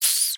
sfx_bunny_squeak_v5.ogg